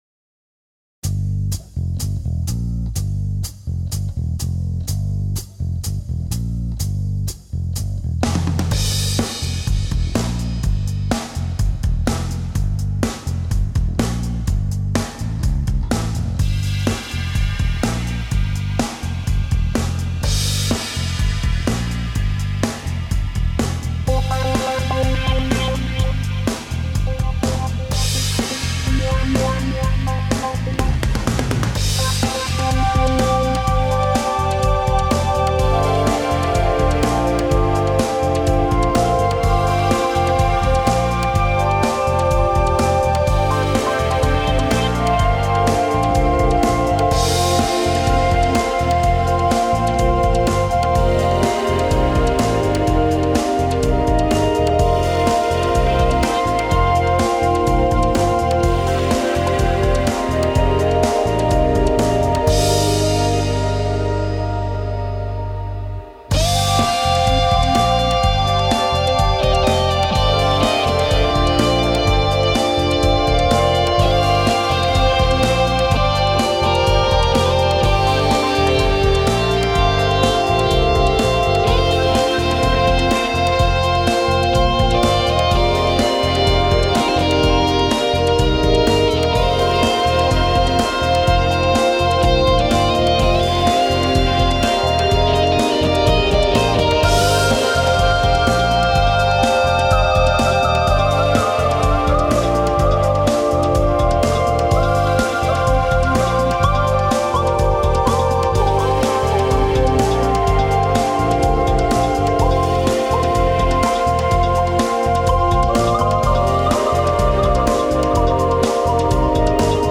As is typical of my brother's work, it was instrumental. Sort of a "Variation On The Carlos Santana Secret Chord Progression" tune.